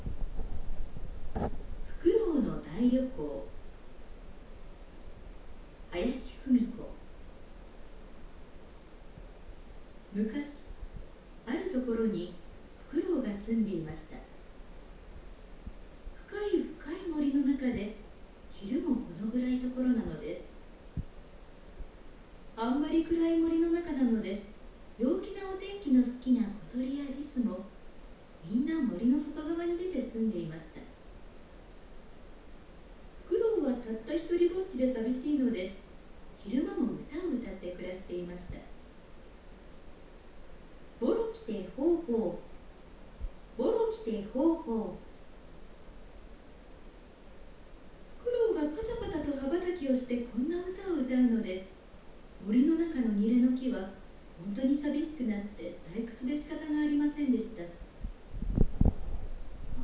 青空文庫から「梟の大旅行」の朗読音声を、NottaとSpeechyで文字起こししてみた結果を見てみましょう。
音声は録音したそのままの状態だよ！
9ｍ先の音までクリアに集音
Langogo miniは最大9メートル、方向は130度の集音が可能です。